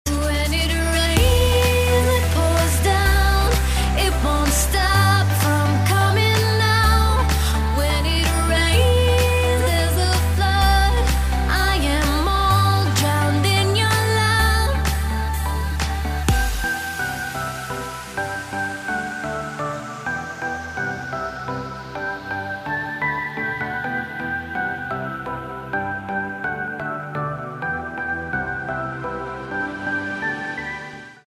• Качество: 128, Stereo
dance
Electronic
EDM
progressive house
красивый женский голос